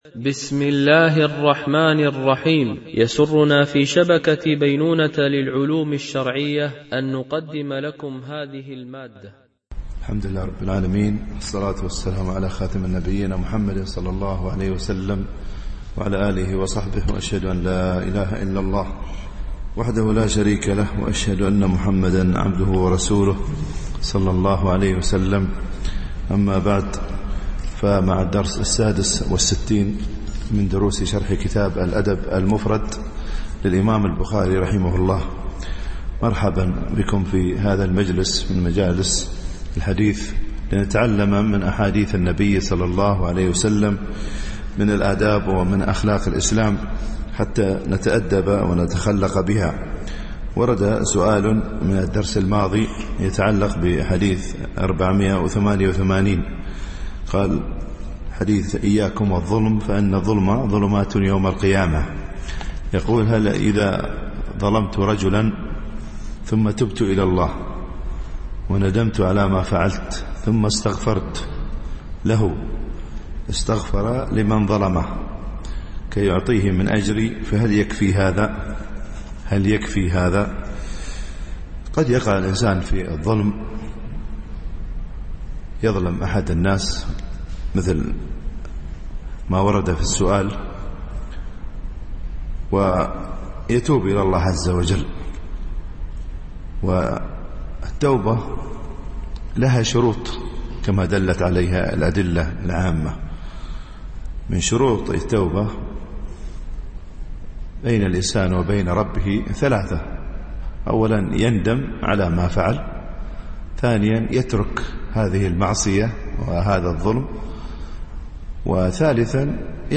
شرح الأدب المفرد للبخاري ـ الدرس 66 ( الحديث 489 -491 )